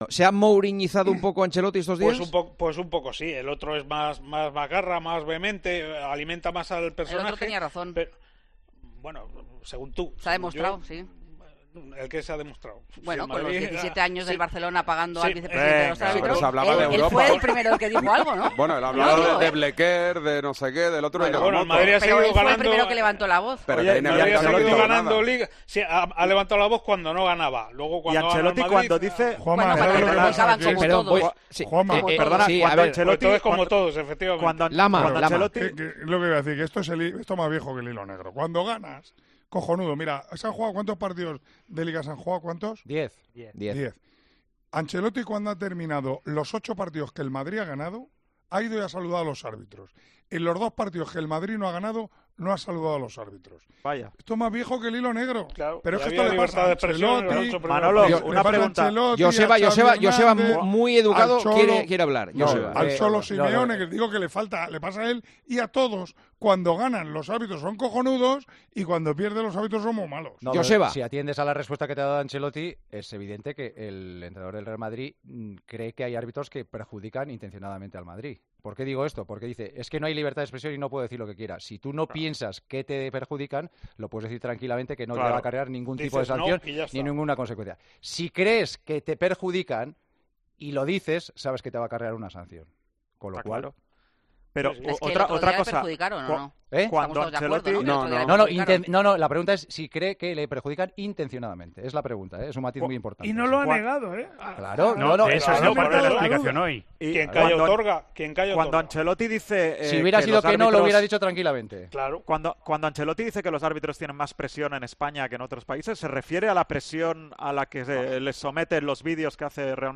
Es por lo que Juanma Castaño preguntó a los tertulianos de 'El Partidazo de COPE' si el italiano "se ha 'Mourinhizado'".